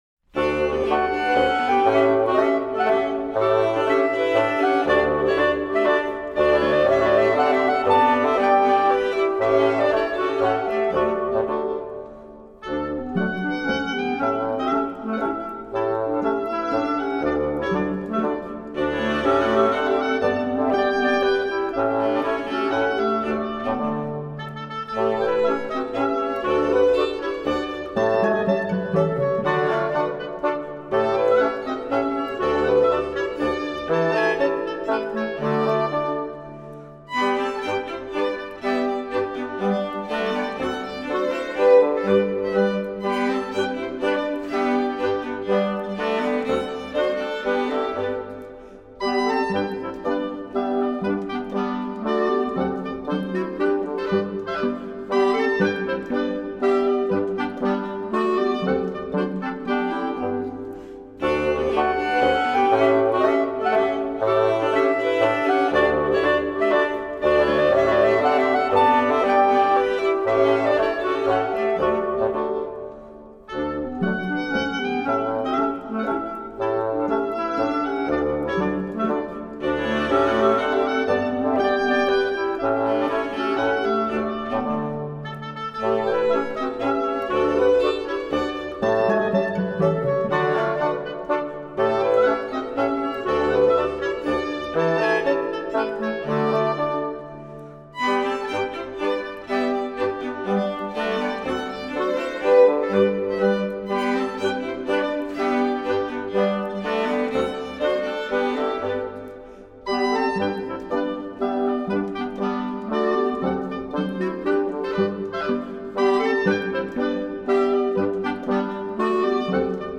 Zurich Boys’ Choir – The most beautiful Swiss folk songs and tunes (Vol. 2)
Trad./Peter Gmünder, arr., André Scheurer.